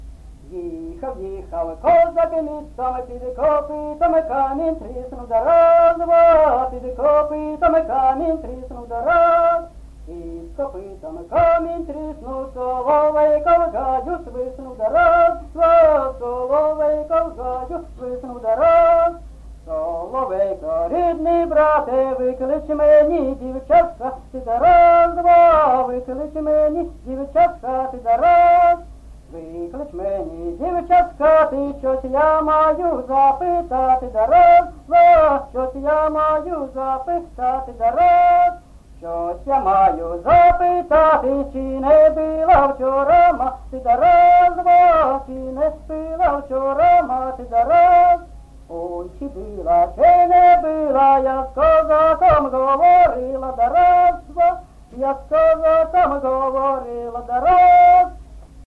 Місце записус-ще Троїцьке, Сватівський район, Луганська обл., Україна, Слобожанщина